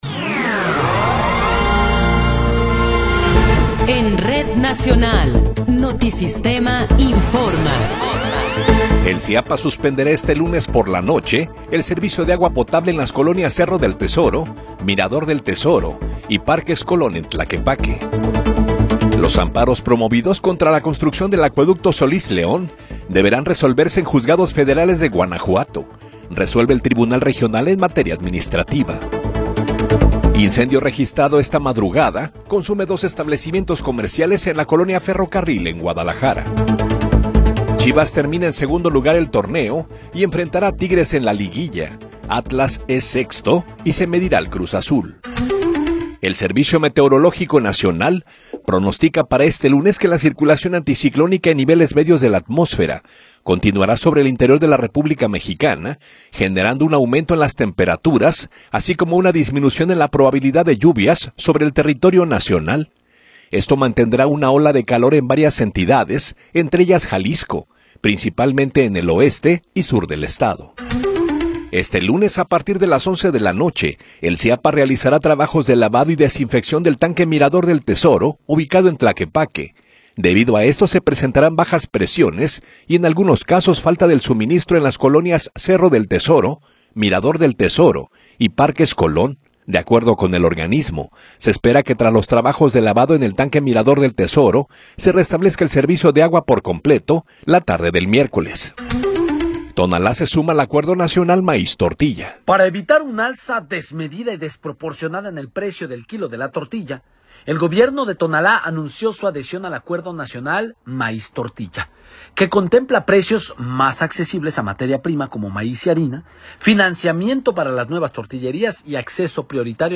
Noticiero 9 hrs. – 27 de Abril de 2026
Resumen informativo Notisistema, la mejor y más completa información cada hora en la hora.